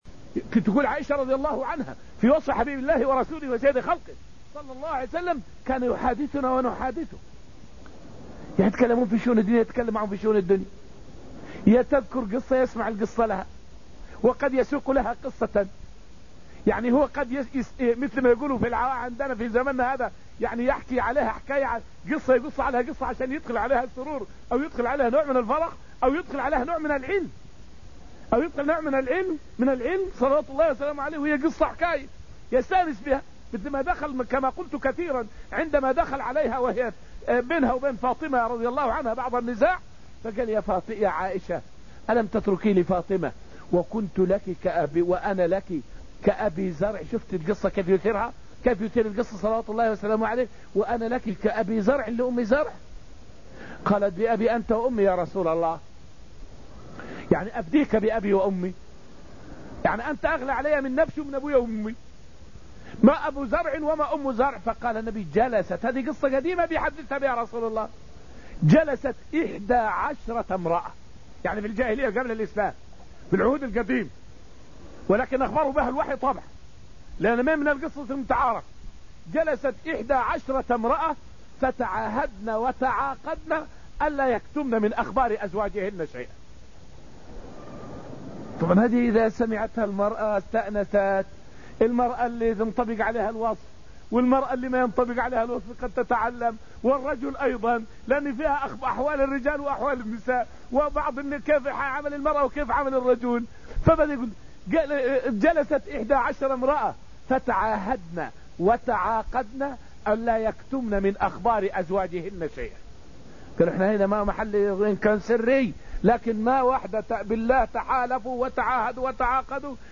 فائدة من الدرس الحادي عشر من دروس تفسير سورة الحشر والتي ألقيت في المسجد النبوي الشريف حول شرح حديث (من سنّ في الإسلام سنّة حسنة فله أجرها).